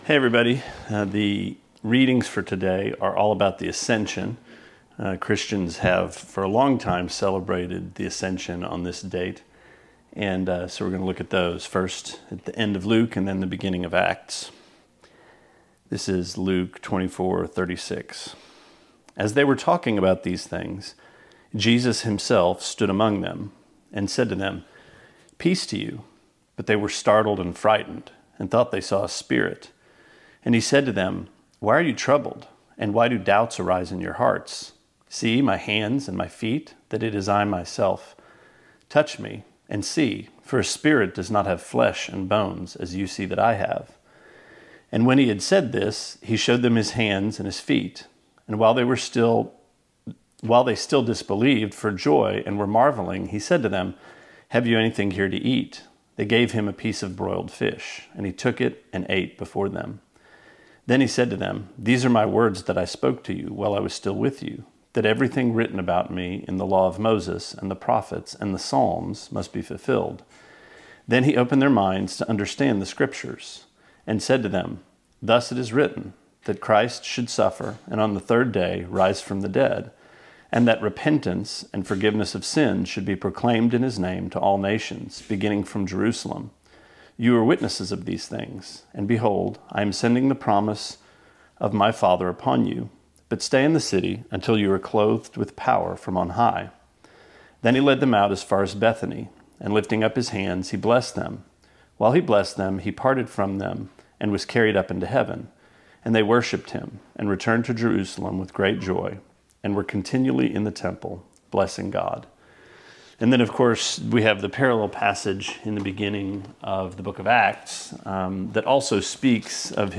Sermonette 5/21: Luke 24:36-53: Ascension